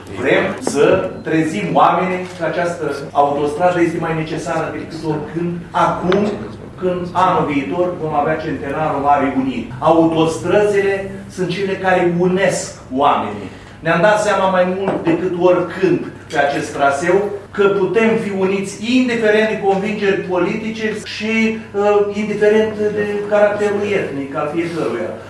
Unul din inițiatorii mișcării